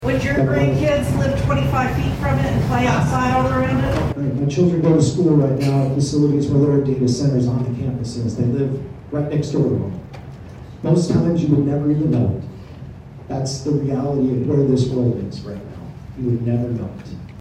In a standing room only crowd that reached full capacity at the Dave Landrum Community Center, 14 Pawhuska residents spoke out against the possibility of a small scale data center coming to Pawhuska.